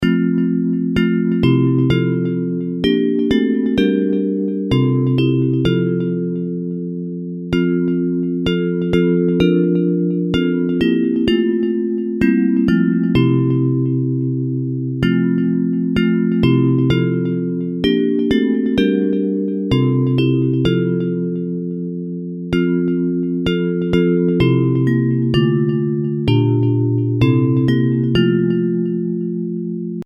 Hymns of praise
Bells Version (.mp3)